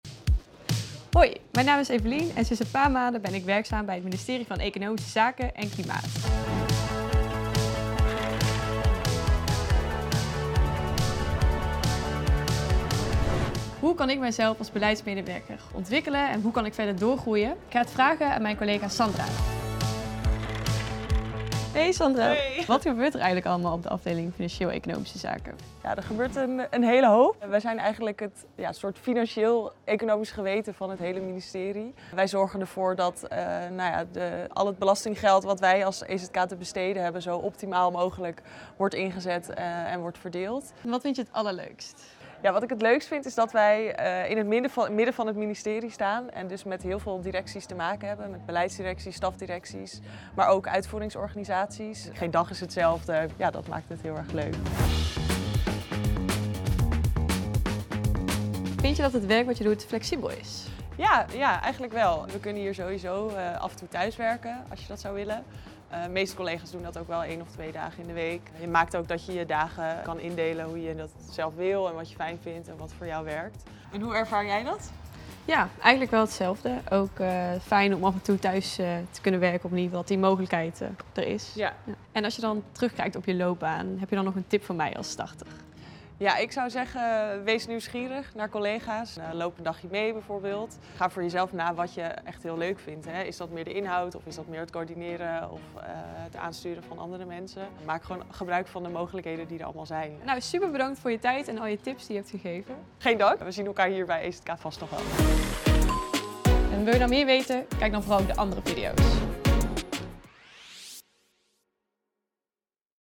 In de videoserie Op Je Plek Bij gaan starters in gesprek met ervaren collega’s over het werken en de loopbaanmogelijkheden bij het ministerie van Economische Zaken (EZ, voorheen het ministerie van Economische Zaken en Klimaat).